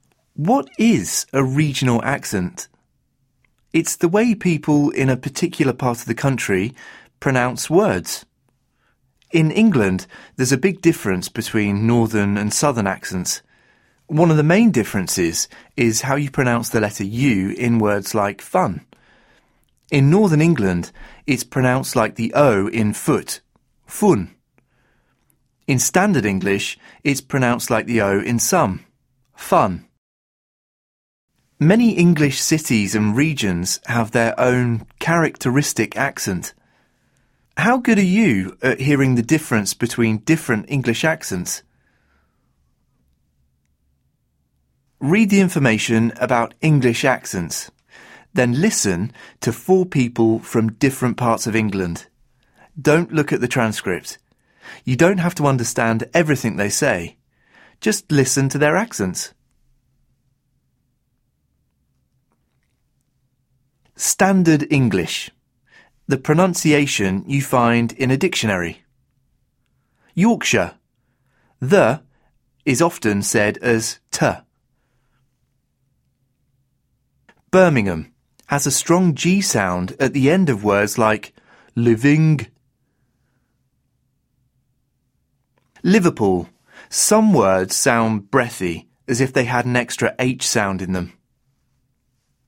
In England there’s a big difference between northern and southern accents.
Many English cities and regions have their own characteristic accent.